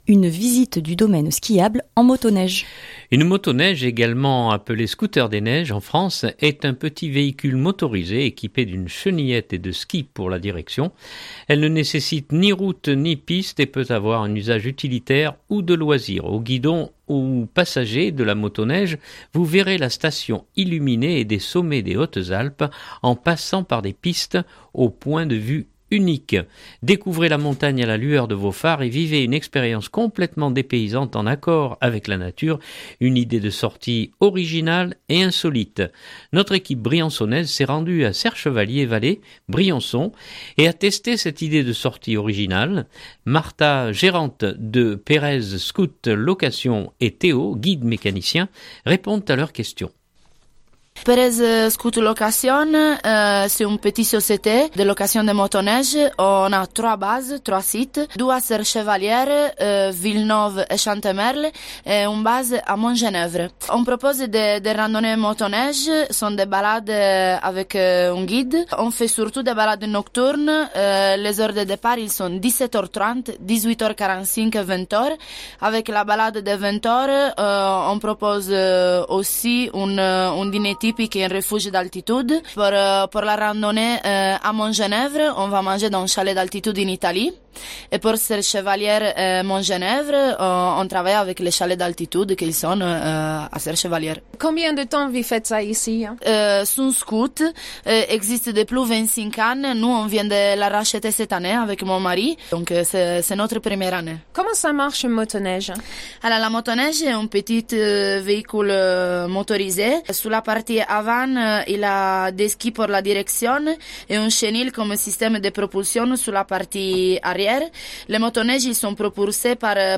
Notre équipe briançonnaise s’est rendue à Serre Chevalier Vallée Briançon et a testé cette idée de sortie originale et insolite.